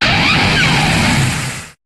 Cri de Torterra dans Pokémon HOME.